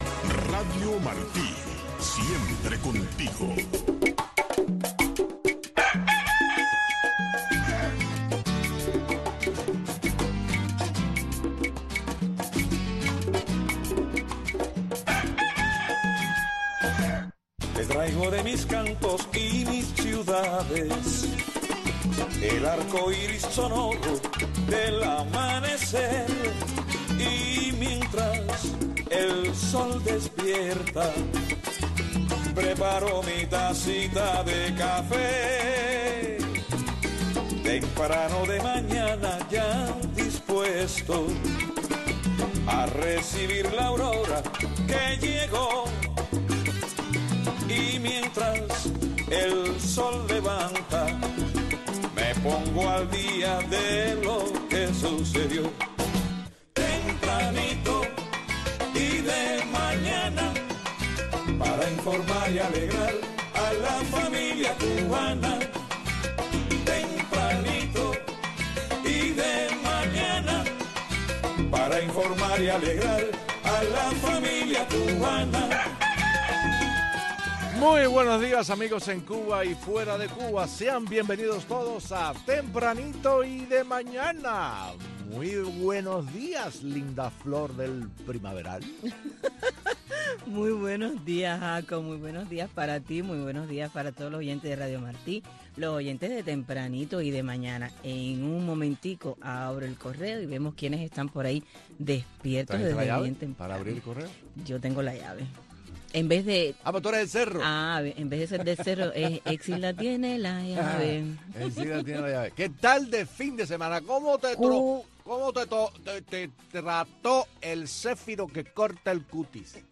Disfrute el primer café de la mañana escuchando a Tempranito, una atinada combinación de noticiero y magazine, con los últimos acontecimientos que se producen en Cuba y el resto del mundo.